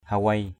/ha-weɪ/ 1. (d.) roi = verge en rotin. hawei asaih hw] a=sH roi ngựa = fouet pour le cheval. ataong mâng hawei a_t” m/ hw] đánh bằng roi. 2....
hawei.mp3